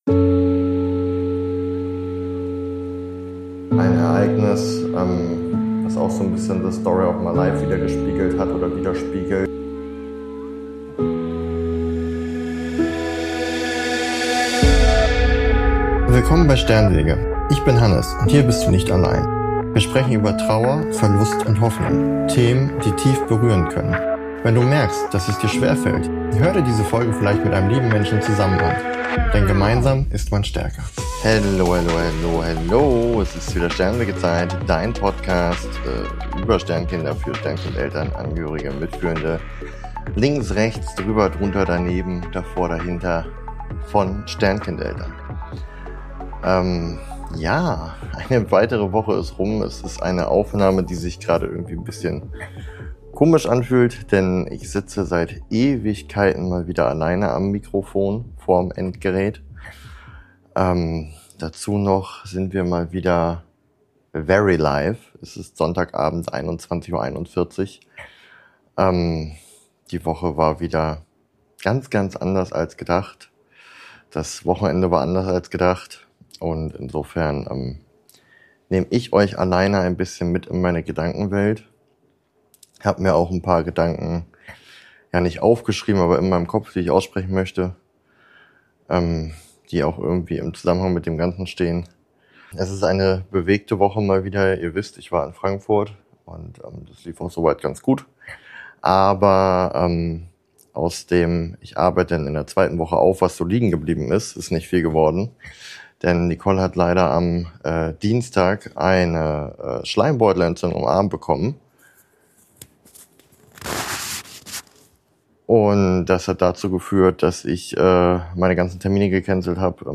Ruhig.
Seit langem sitze ich mal wieder alleine am Mikrofon und nehme euch mit in eine Woche, die mich mental ziemlich gefordert hat.